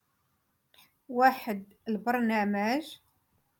Moroccan Dialect- Rotation Three- Lesson Eight